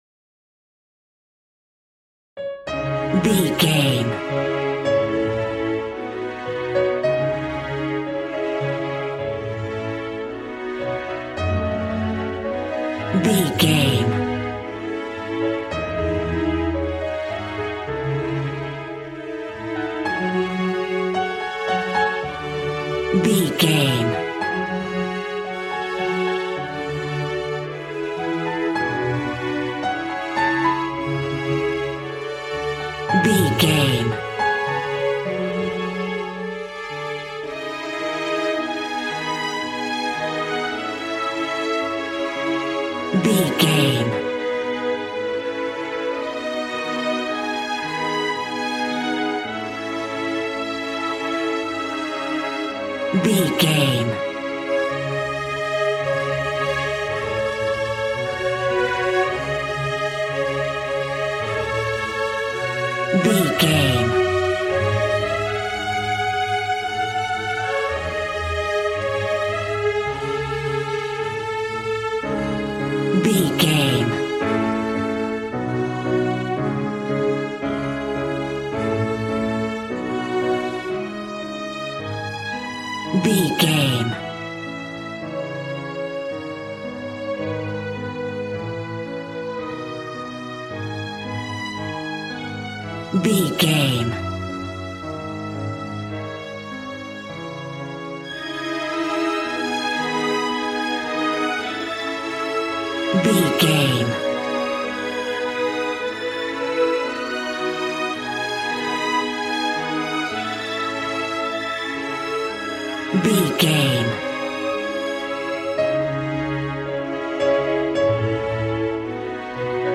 Regal and romantic, a classy piece of classical music.
Aeolian/Minor
A♭
regal
cello
violin
strings